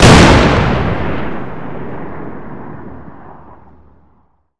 Index of /server/sound/weapons/explosive_m67
m67_explode_6.wav